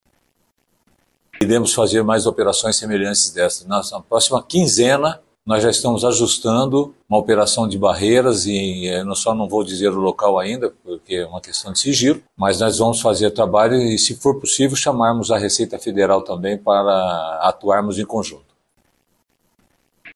Ainda segundo o delegado, uma outra operação deve acontecer na região nas próximas semanas.